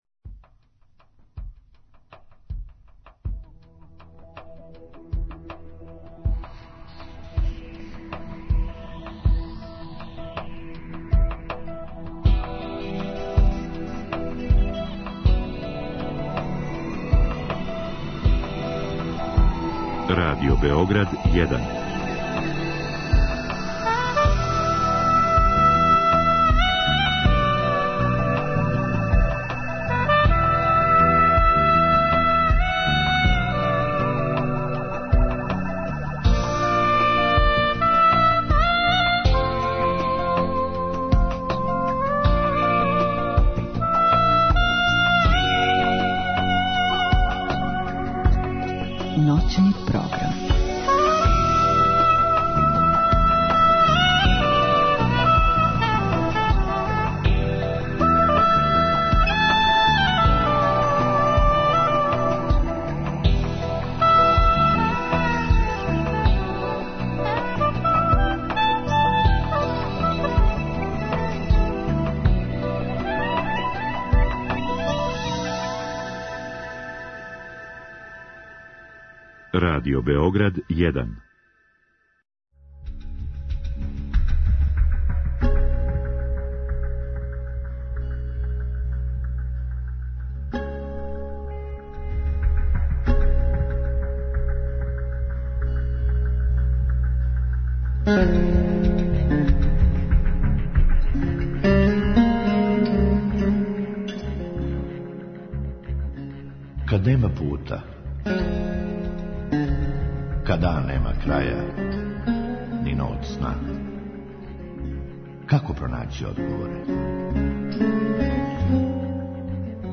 Када и како започињемо путовање самоокривљавања и како га можемо прекинути? Има ли разлике између кривице и стида и има ли имуних на осећање кривице - само су нека од питања на која ћемо покушати да одговоримо у првом сату емисије, а у другом можете поделити своје утиске о томе или нечем другом што вас мучи а има везе с психологијом.